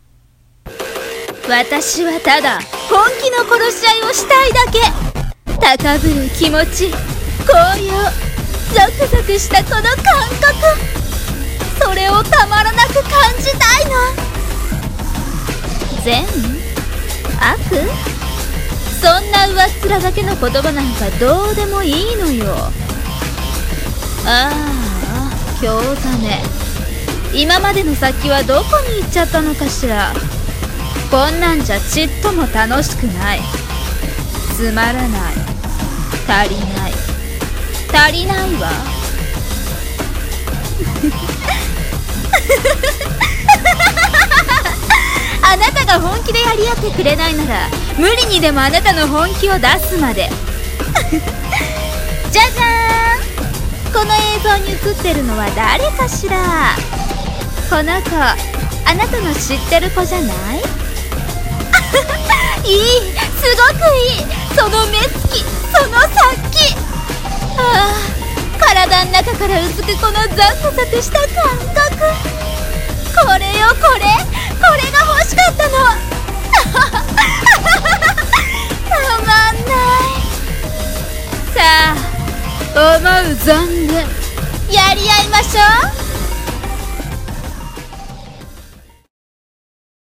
【一人声劇】タリナイ【悪役】